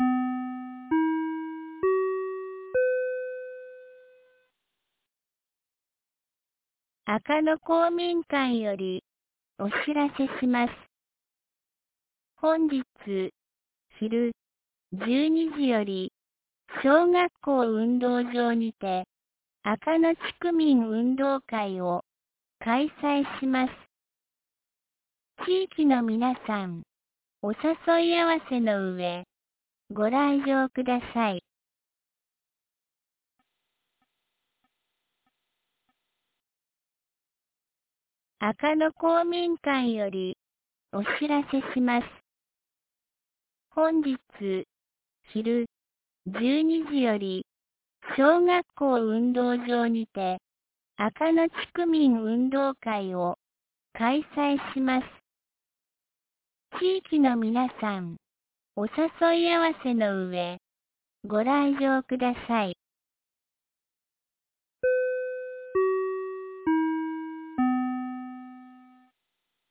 2024年10月20日 10時01分に、安芸市より赤野へ放送がありました。
放送音声